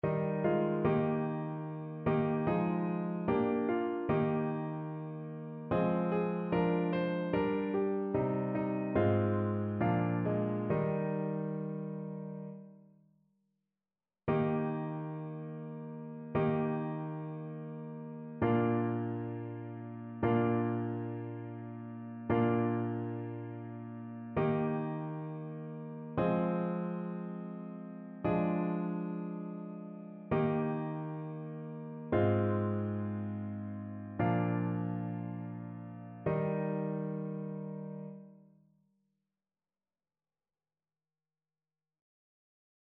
ChœurSopranoAltoTénorBasse
annee-b-temps-de-noel-sainte-famille-psaume-104-satb.mp3